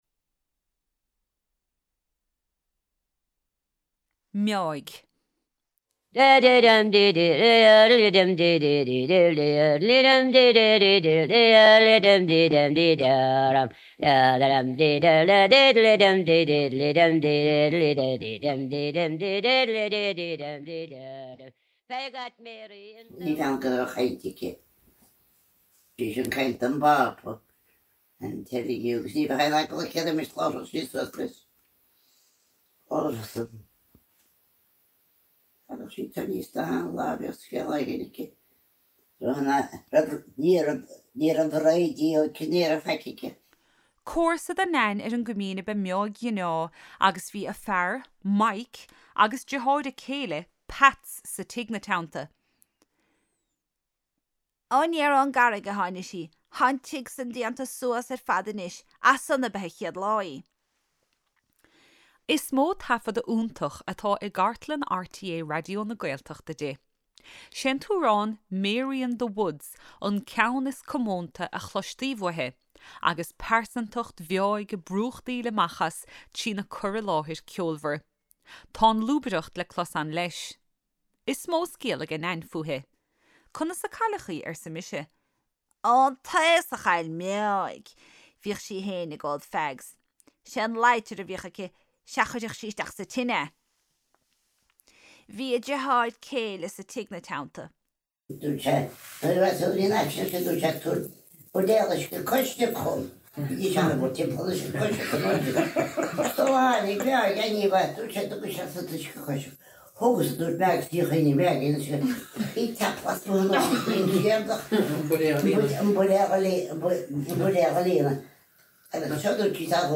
Nain (closleabhar)